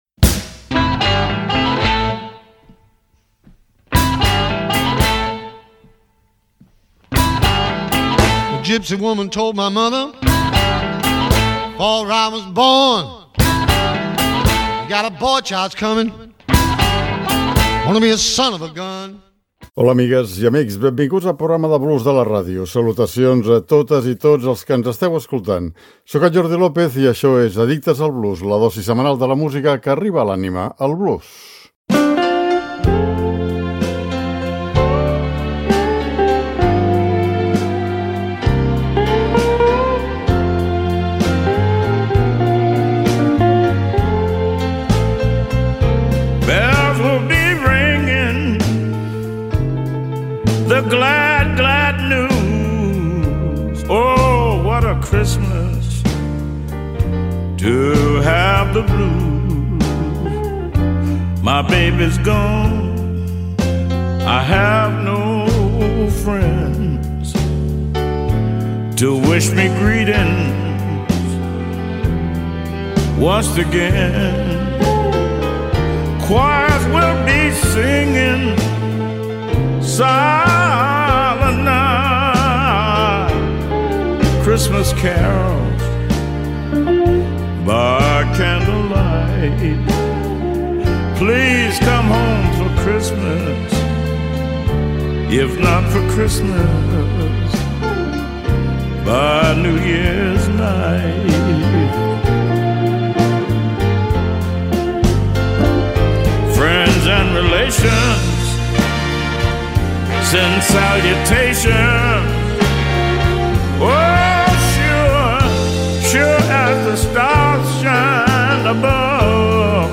amb nadales a ritme de blues.